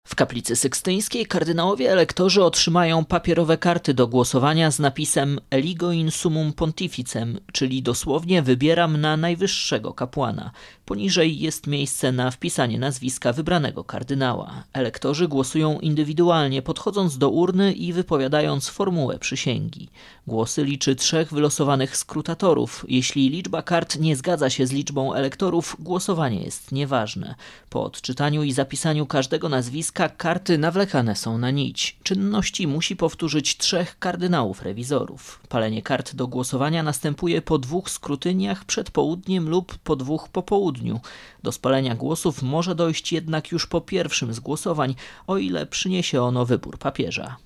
AUDIO: Relacje dotyczące konklawe.